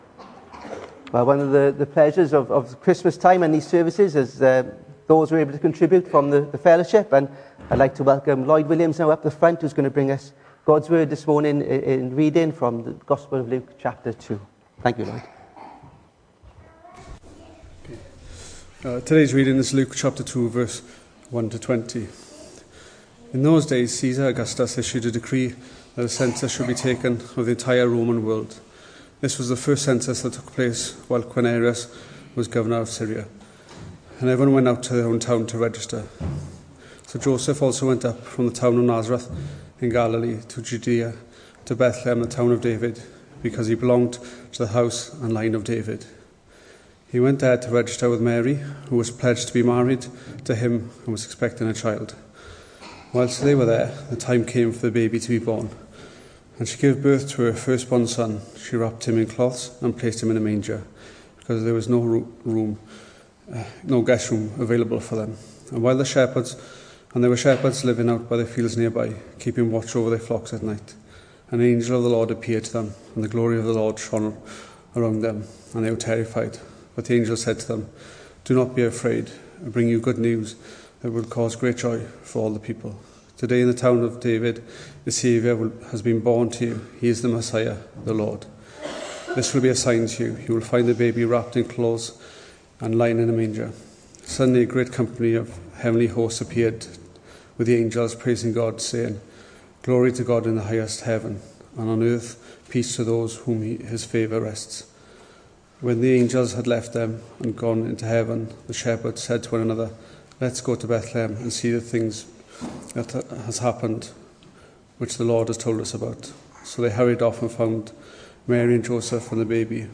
The 21st of December saw us host our Sunday morning service from the church building, with a livestream available via Facebook.